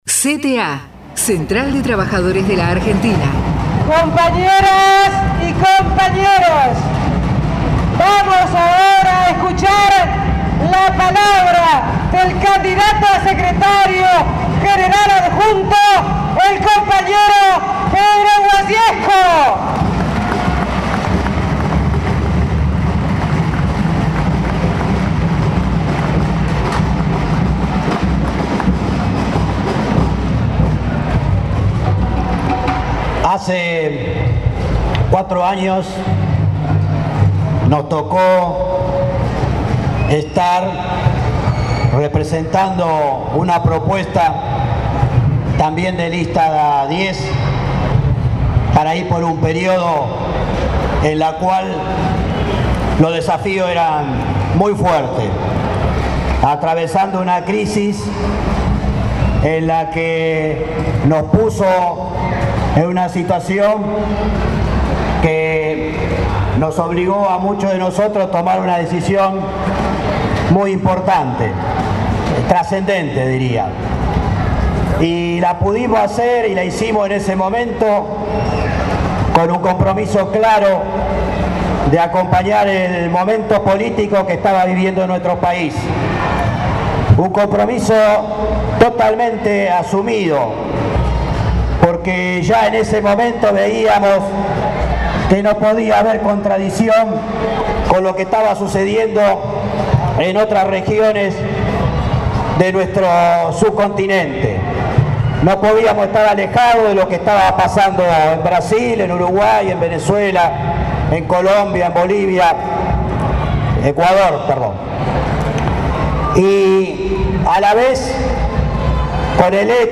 ACTO VILLA DOMÍNICO